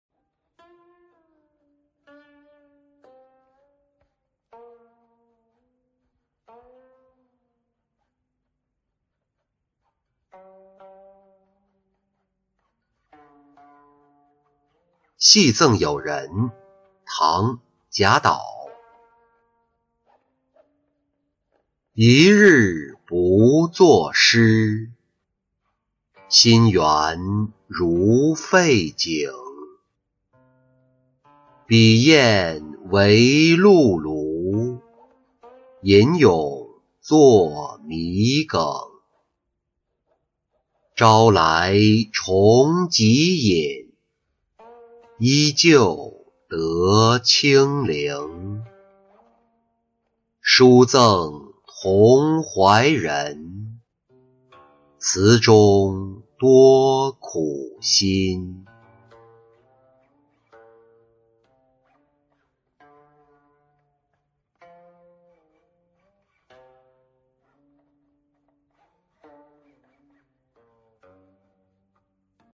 戏赠友人-音频朗读